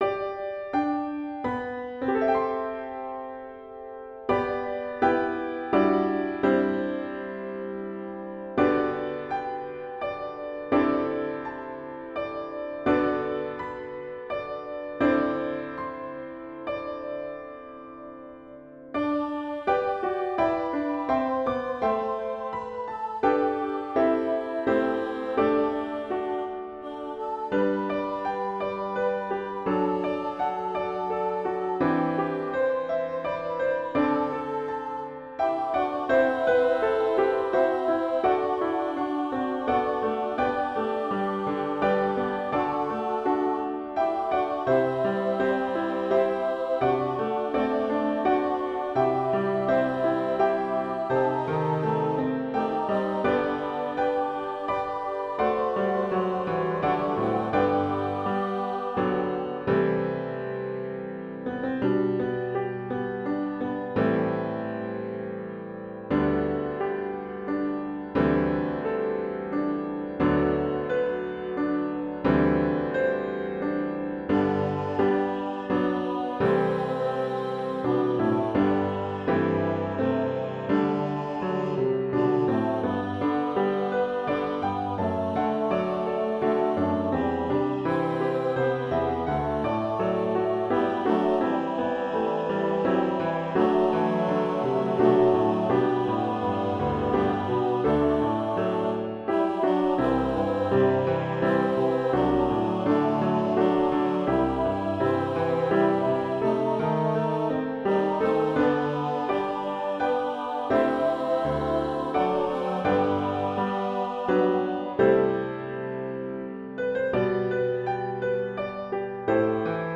SATB Choral Arrangement
Reference Track (Lyrics Below)
SATB Choral Anthem with Piano